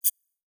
Holographic UI Sounds 77.wav